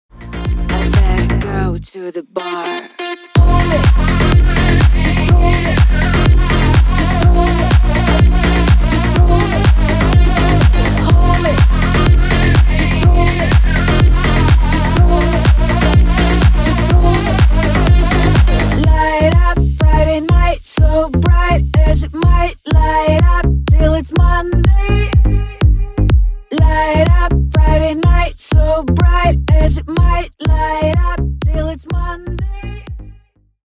поп , клубные